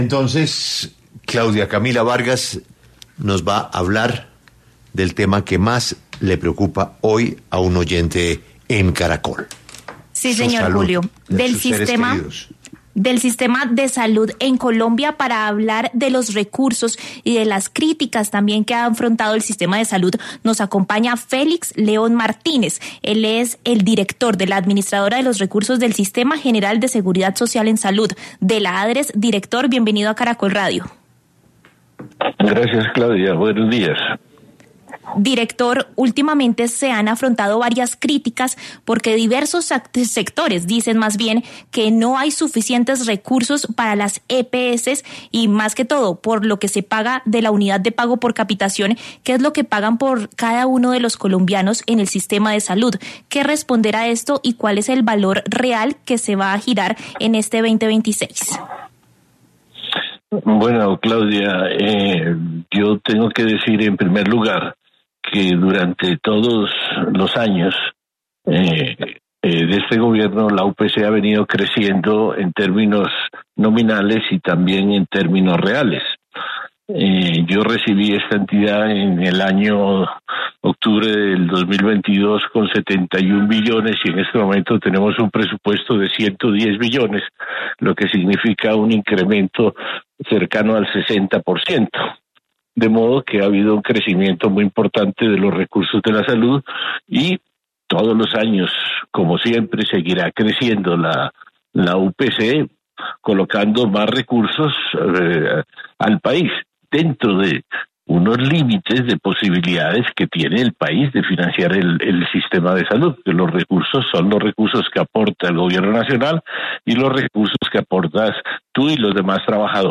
En diálogo con 6AM W, el director de la ADRES, Félix León Martínez, confirmó los acuerdo con ese gestor farmacéutico.